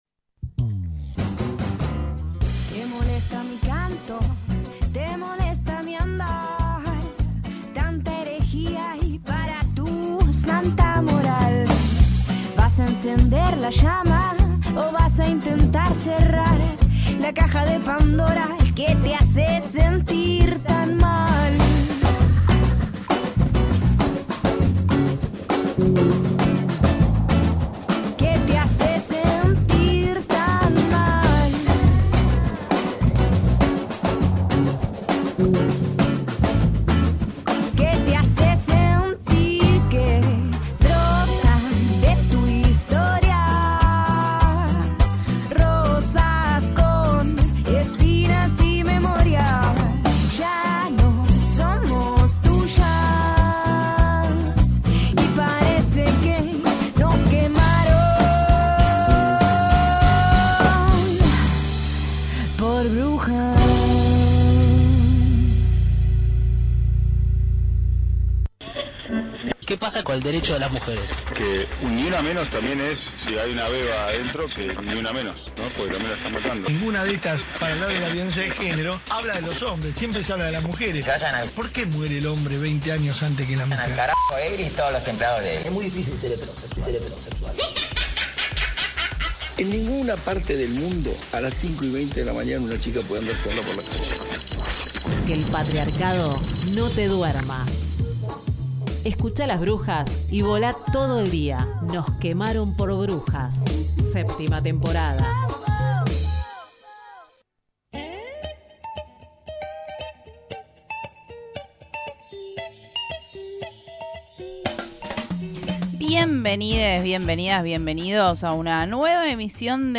Y música para volar!